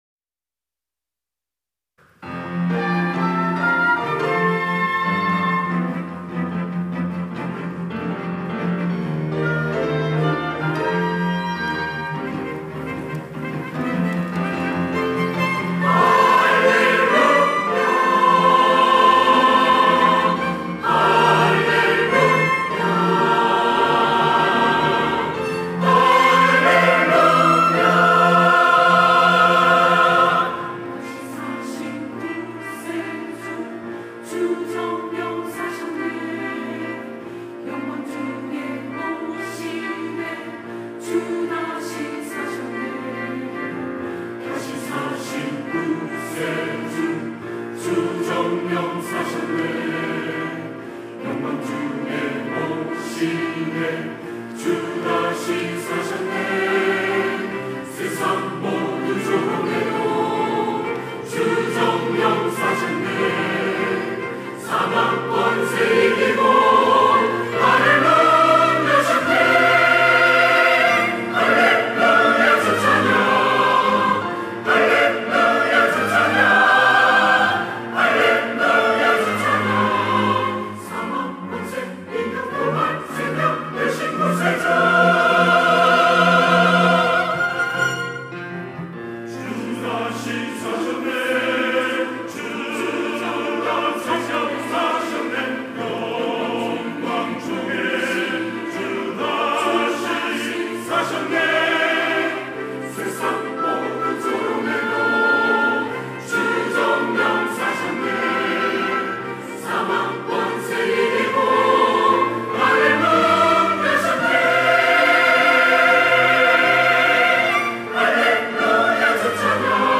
할렐루야(주일2부) - 다시 사신 구세주
찬양대